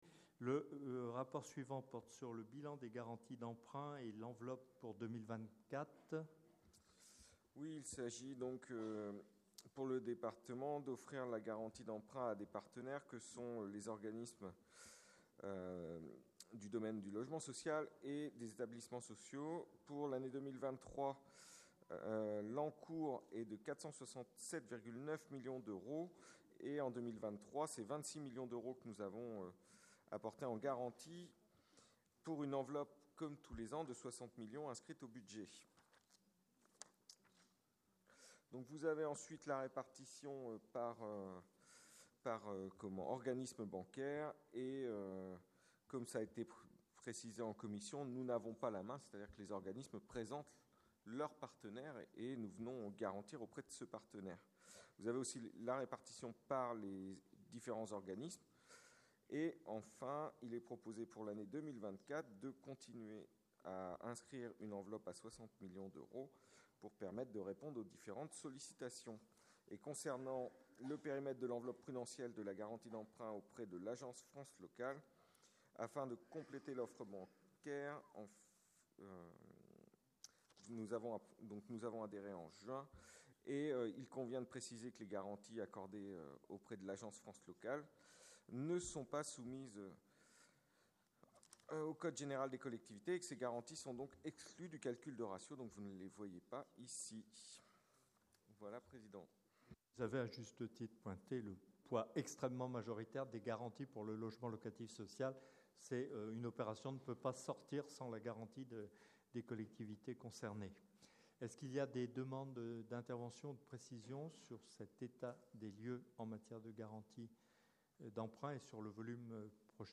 • Assemblée départementale du 07/02/24